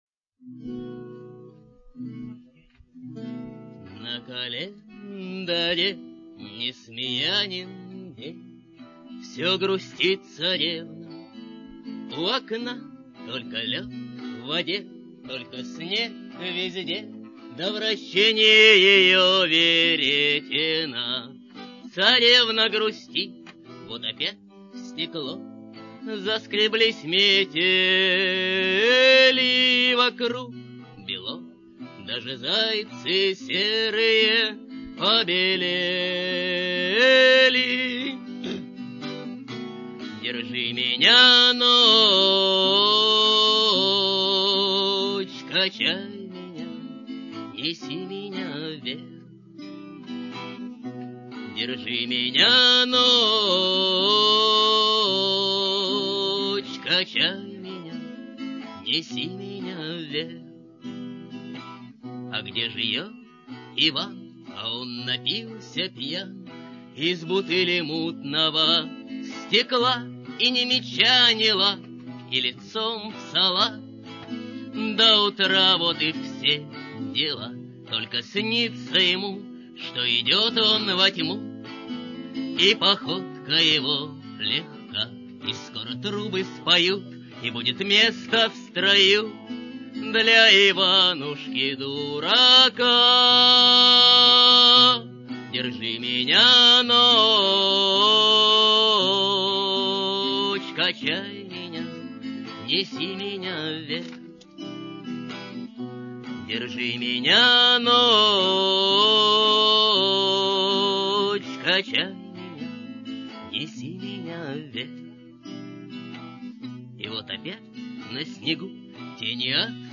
Сцена